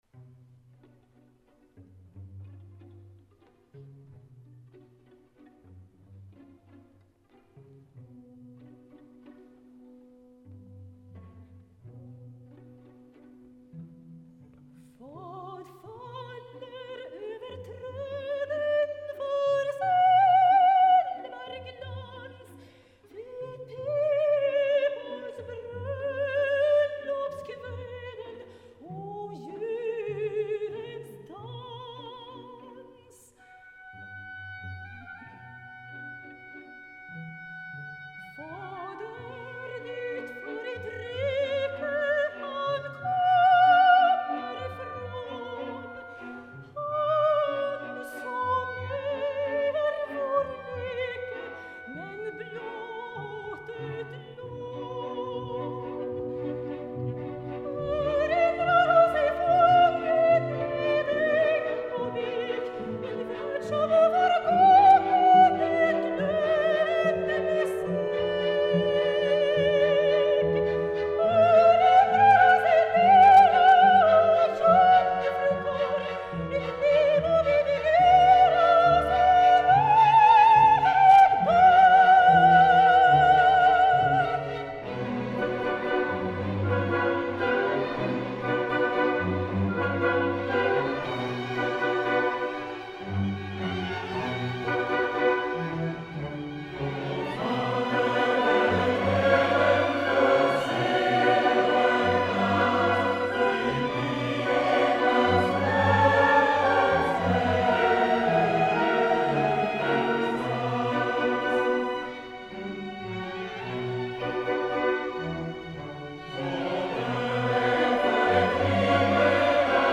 2007-09-29 Konsert Nicolai kyrka i Örebro, Örebro Orkesterförening
Lars-Erik Larsson: Förklädd Gud, Andantino quasi allegretto